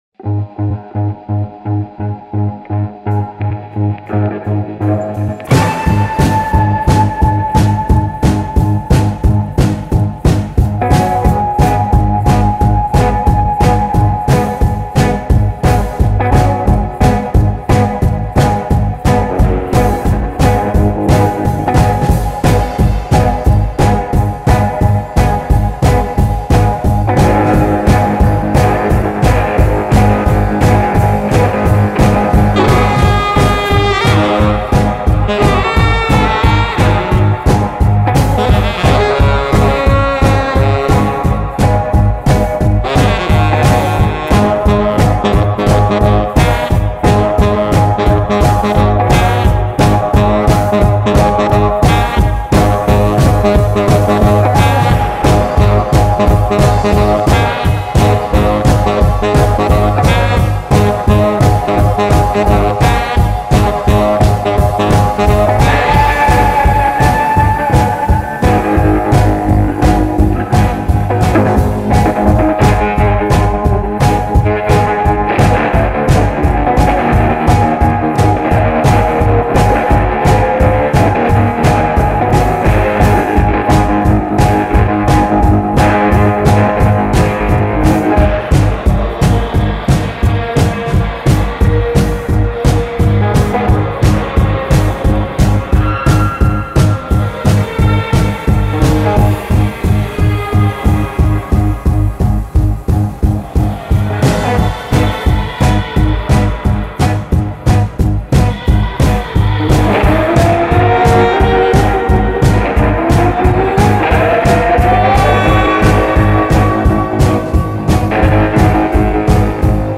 drums
guitar
saxophone